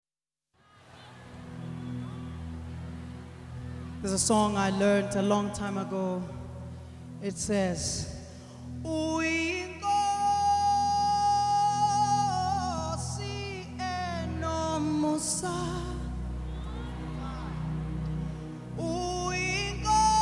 Gospel-infused